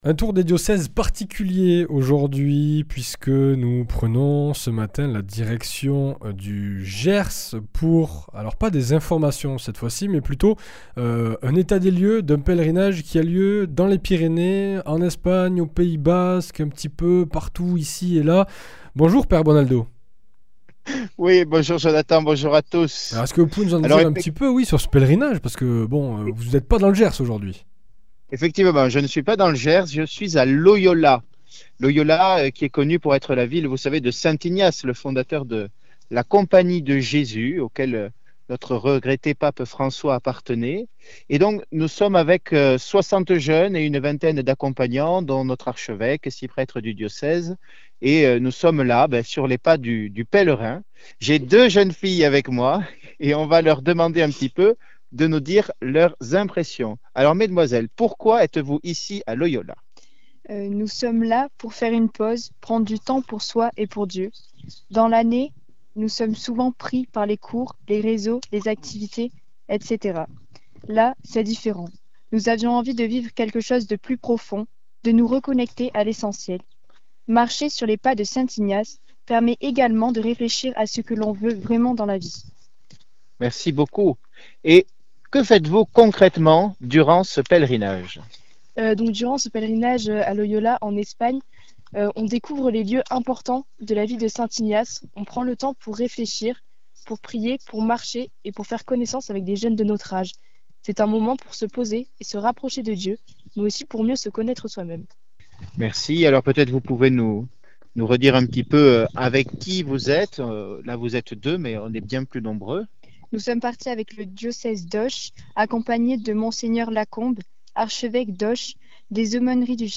Direction Loyola avec des témoignages de jeunes pèlerins